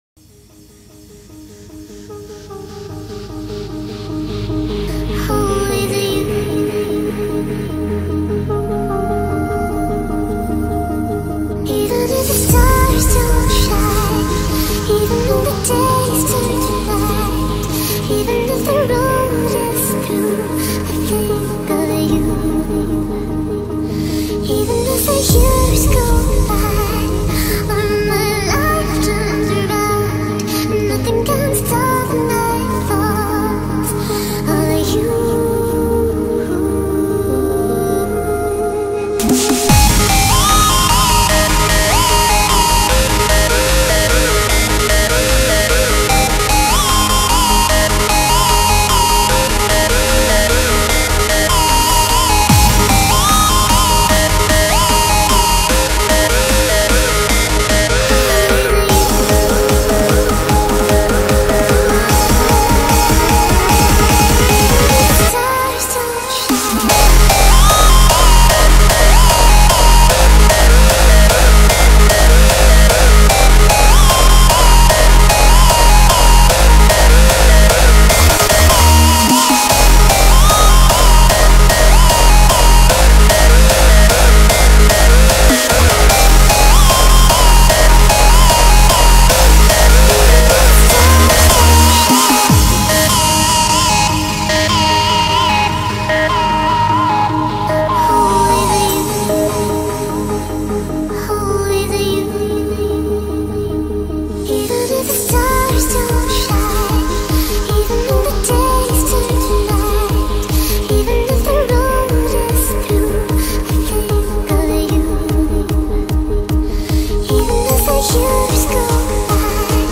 Index of: /data/localtracks/Hardstyle/